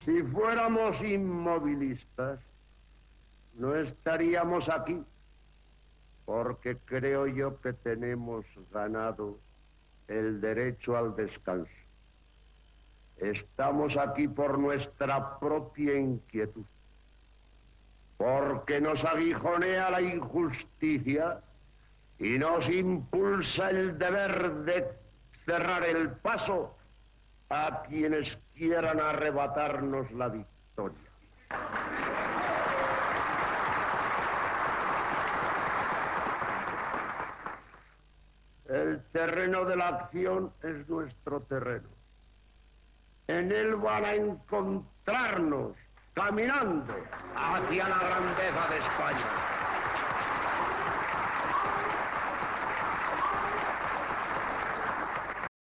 opinió de J.A. Girón, un dels màxims representants del bunker